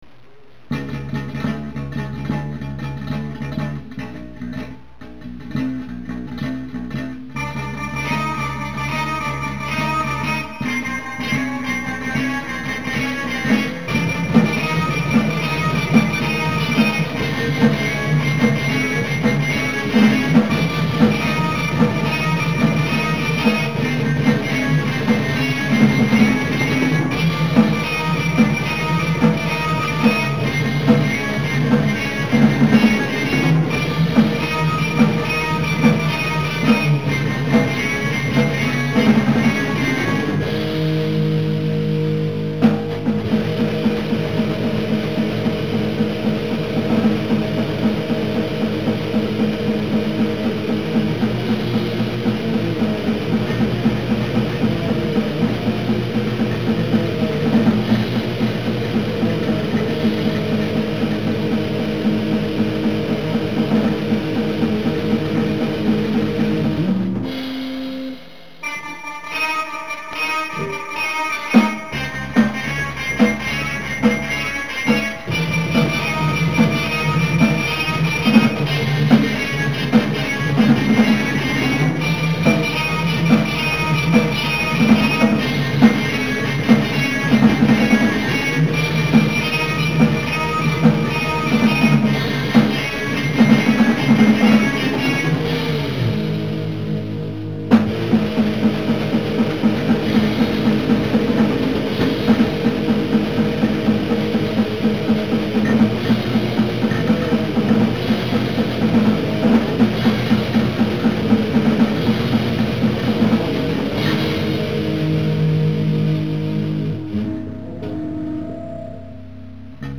Instrumental Demo